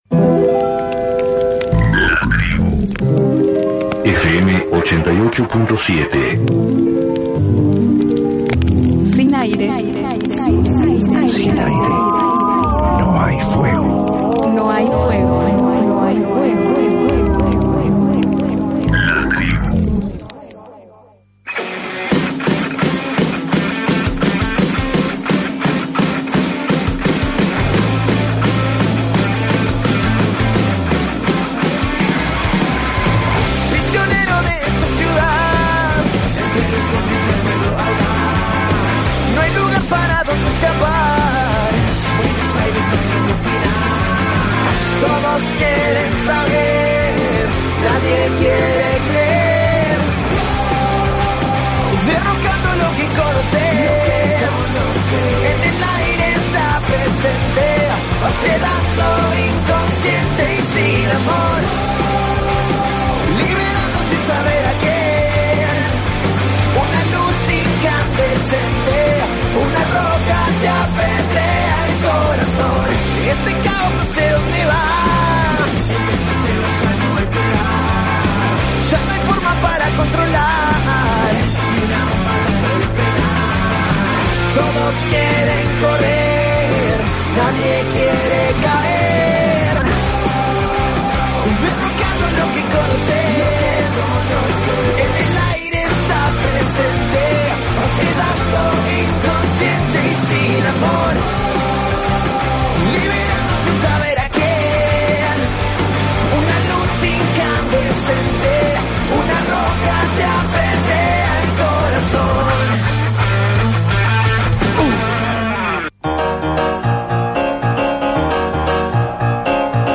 Luis Zamora: Entrevista en vivo Nos visitó el abogado, militante y referente de las luchas por derechos humanos, Luis Zamora. Sus perspectivas en este contexto político. Alianzas y posicionamientos frente al macrismo.